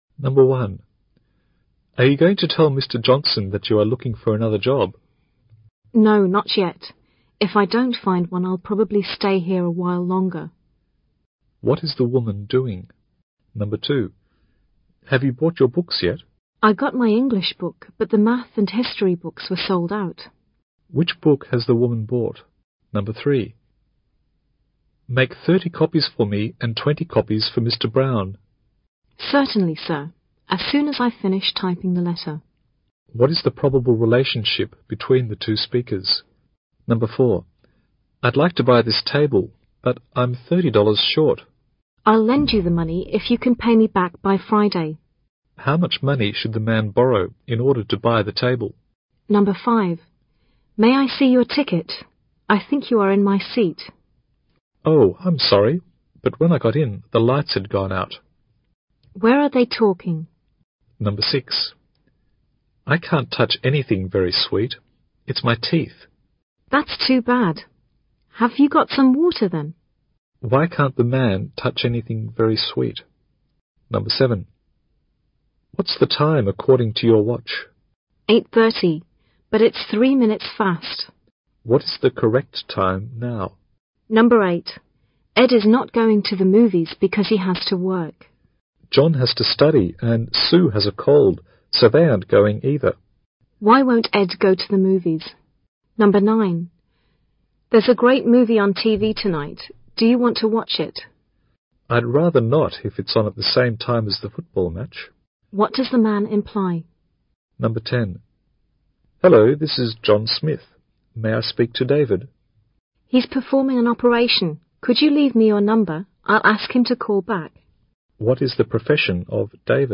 英语六级听力考试预测模拟练习Model Test Seven